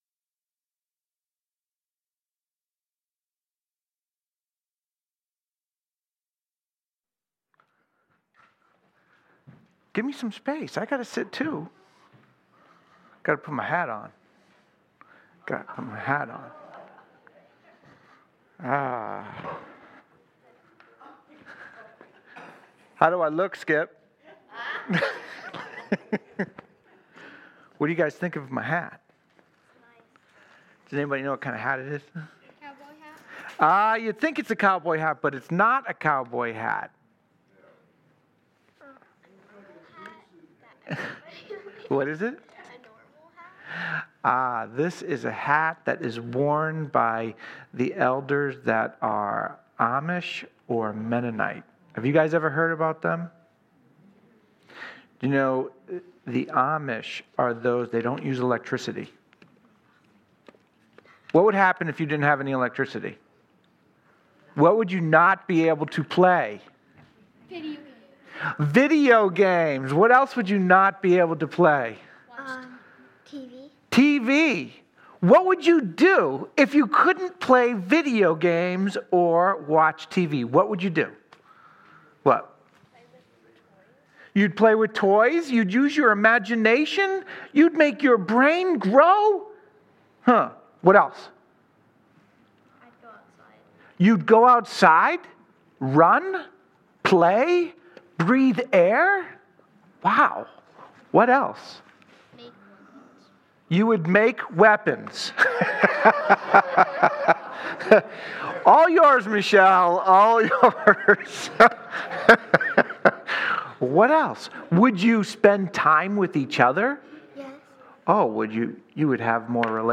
Safe Harbor Community Church Sermons